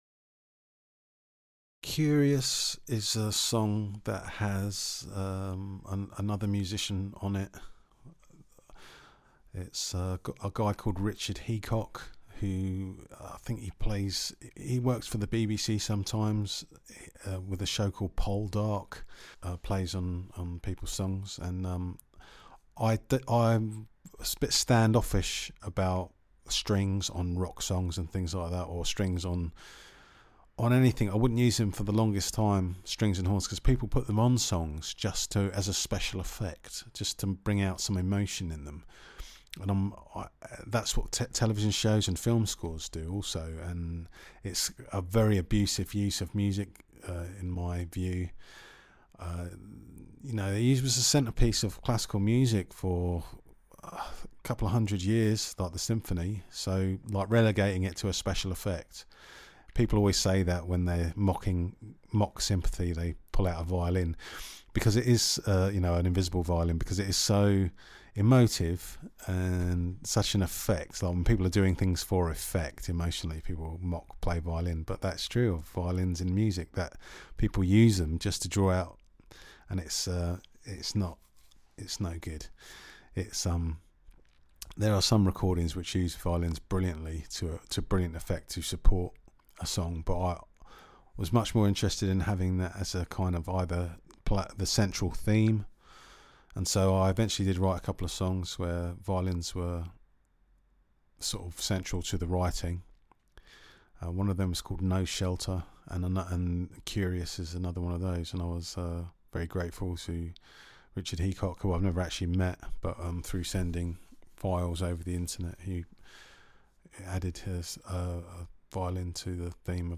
Violin